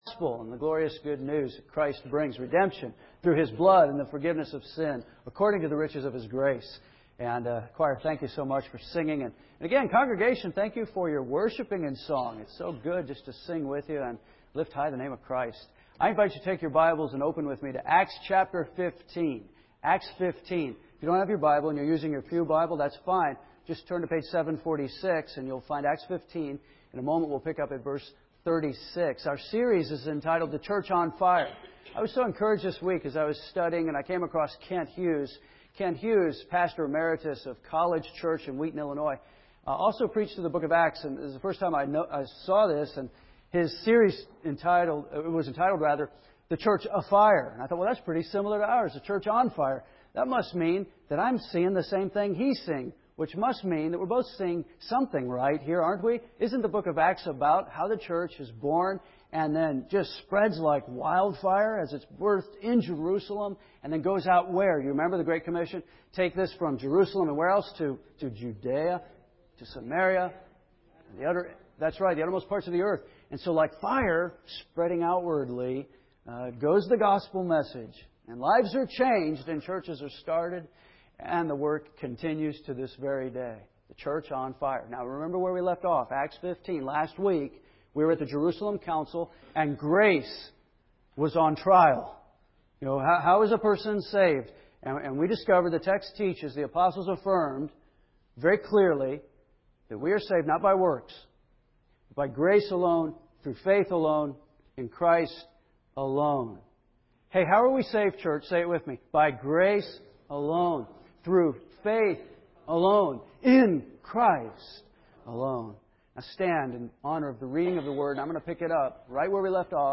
We are preaching our way, verse-by-verse, in our expository preaching series through the book of Acts.